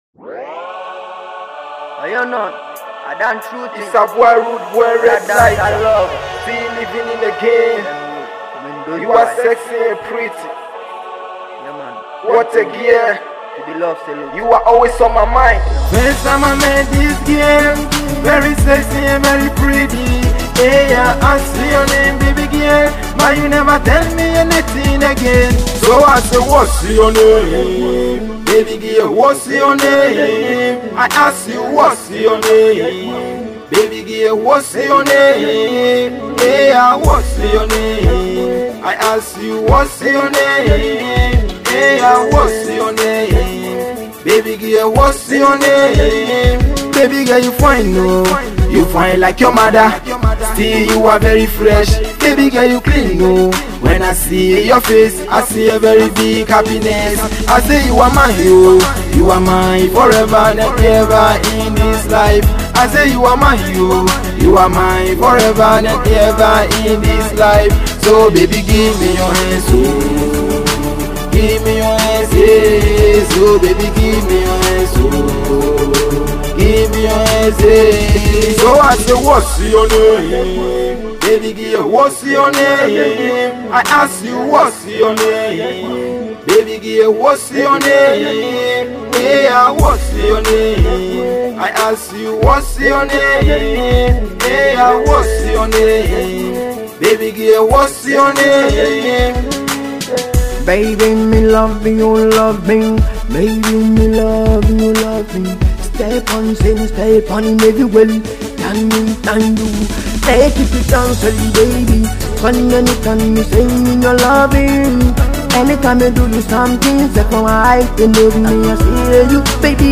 Reggae/Dancehall
Brand new Jam by upcoming dancehall artist